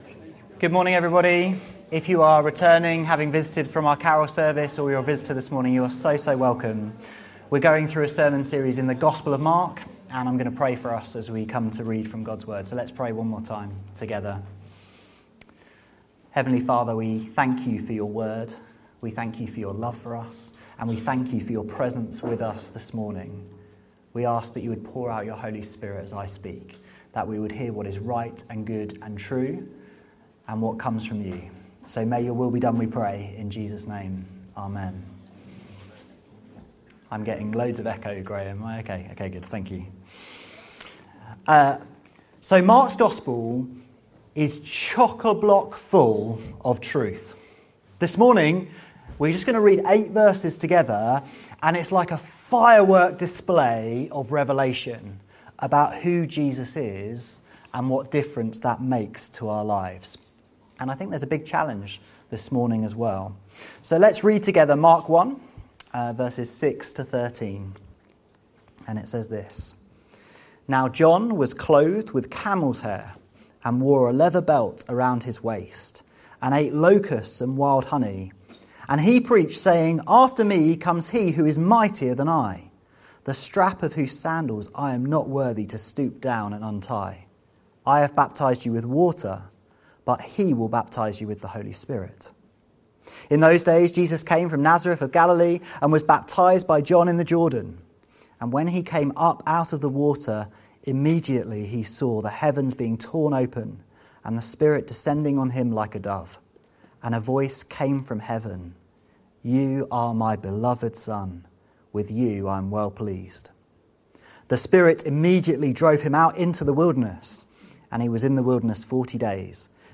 This sermon proclaims glorious truths about Jesus seen in these verses.